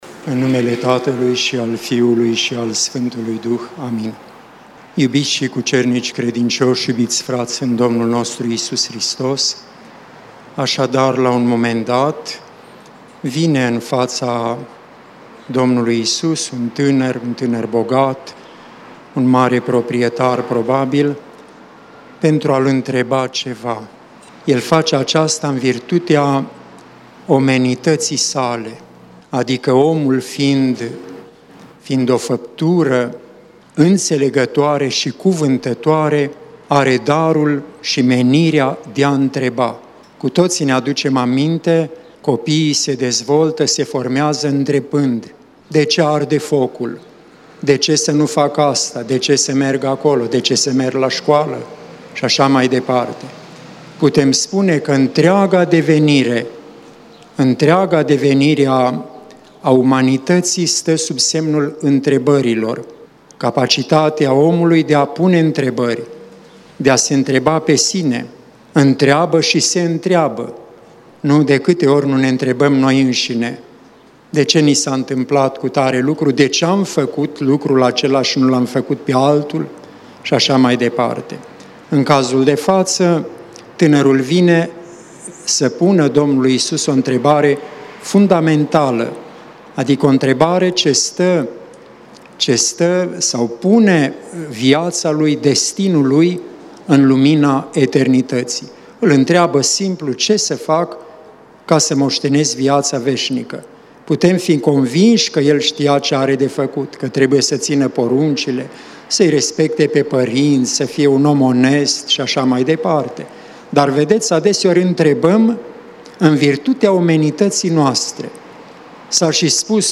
Cuvânt de învățătură
rostit la Catedrala Mitropolitană din Cluj-Napoca, în duminica a 12-a după Rusalii, 30 august 2020.